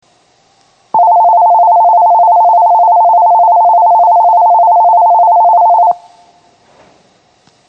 仙石線ホームは地下にあり、通常のROMベルを使用しています。
１０番線発車ベル